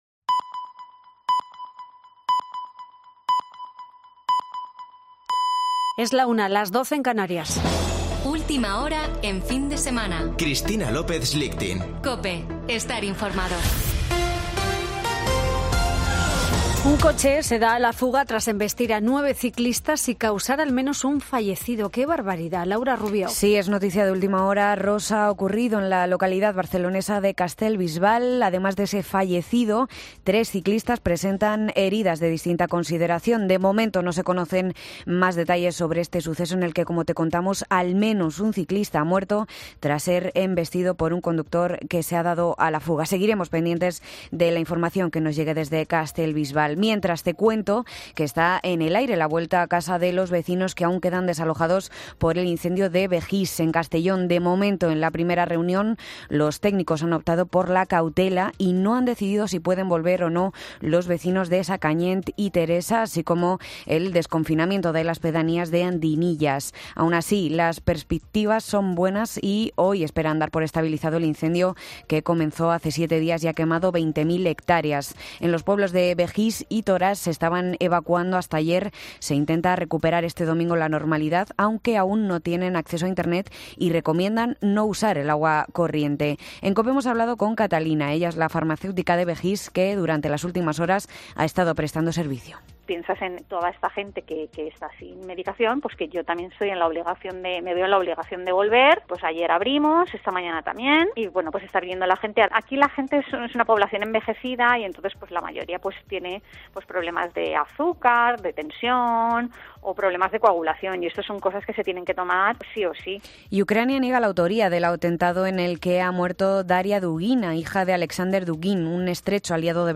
Boletín de noticias de COPE del 21 de agosto de 2022 a la 13.00 horas